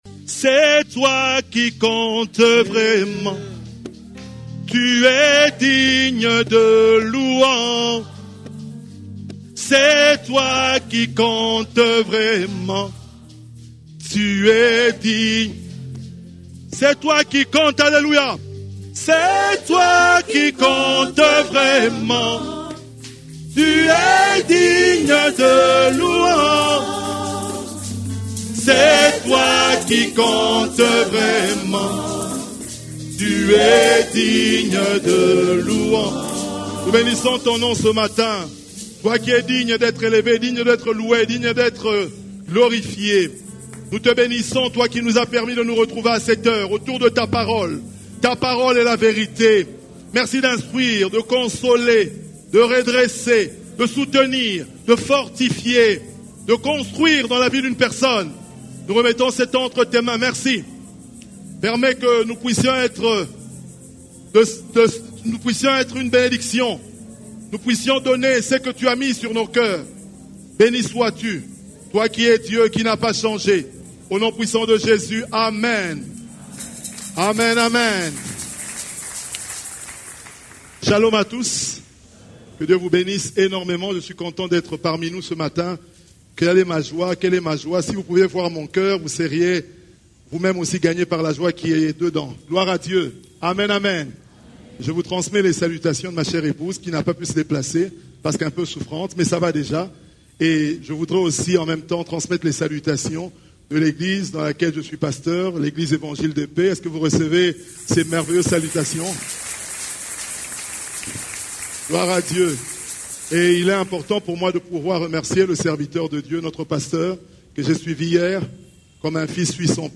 CEF la Borne, Culte du Dimanche, Comment faire face à l'adversité